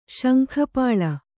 ♪ śankha pāḷa